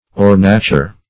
Search Result for " ornature" : The Collaborative International Dictionary of English v.0.48: Ornature \Or"na*ture\, n. [L. ornatura.]
ornature.mp3